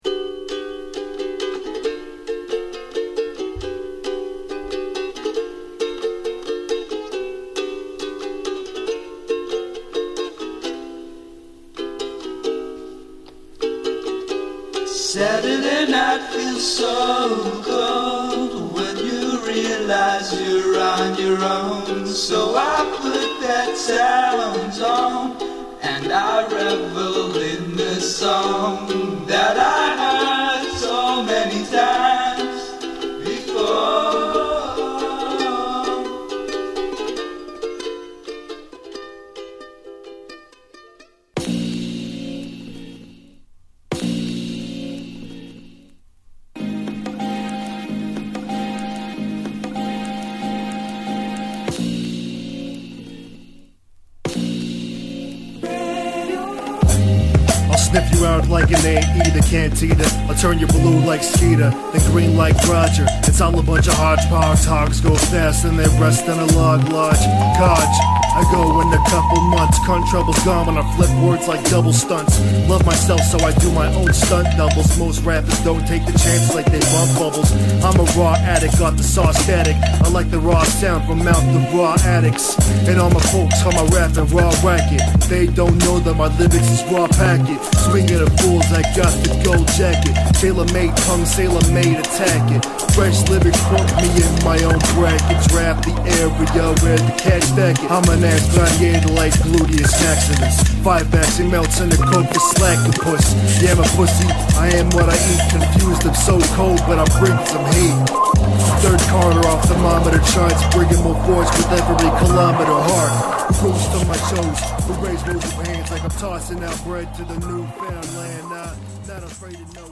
不思議な心地よさに包まれる1枚です！